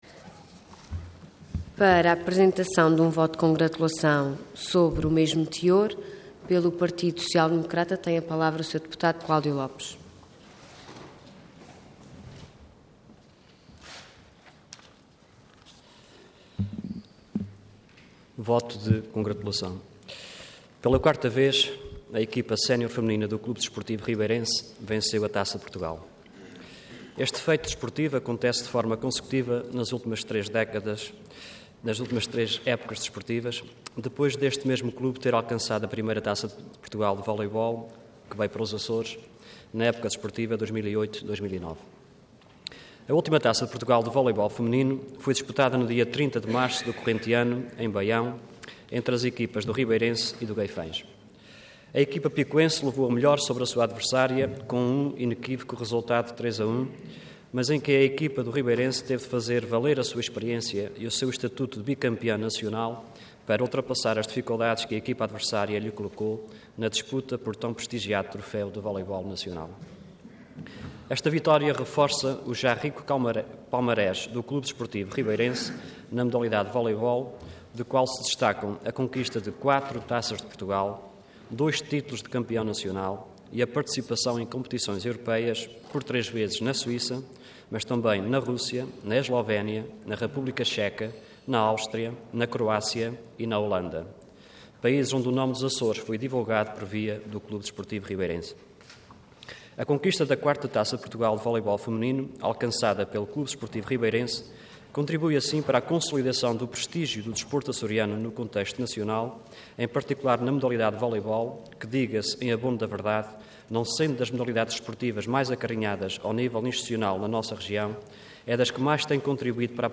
Intervenção Voto de Congratulação Orador Cláudio Lopes Cargo Deputado Entidade PSD